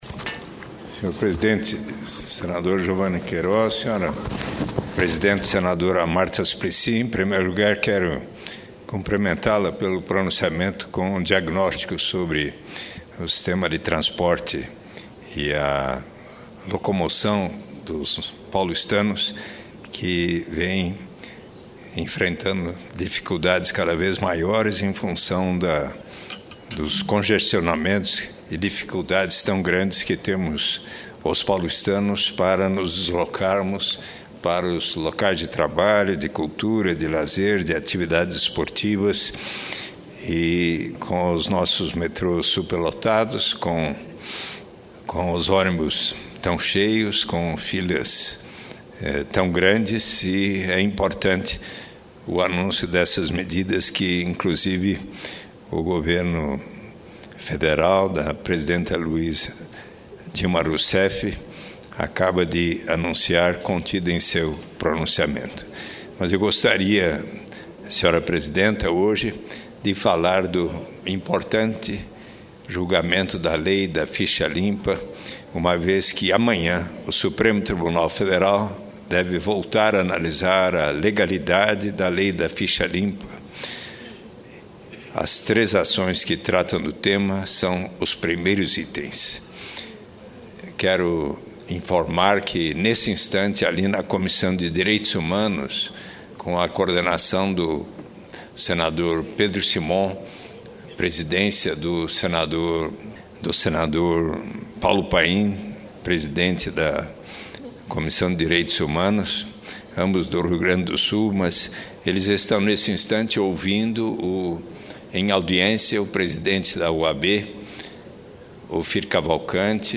Eduardo Suplicy fala da importância da legalidade da Lei da Ficha Limpa
Plenário